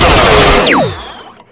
rifle1.ogg